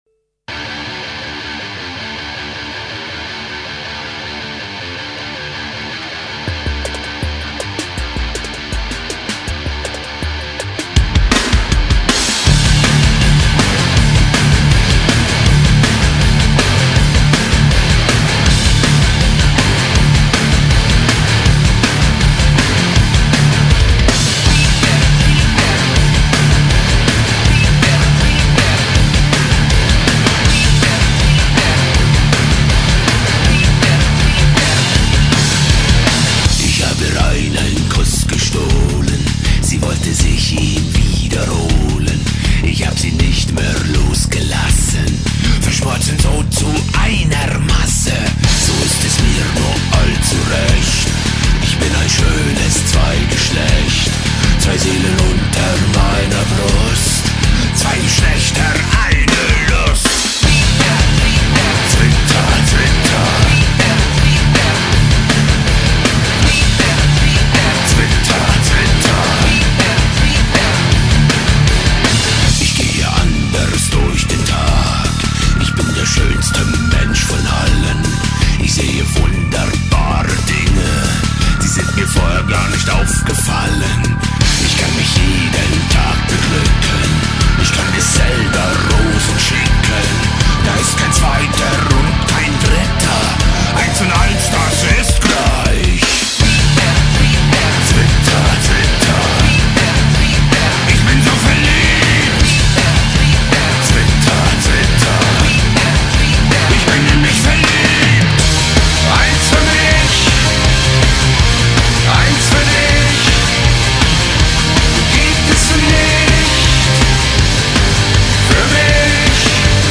Категория: Рок, Альтернатива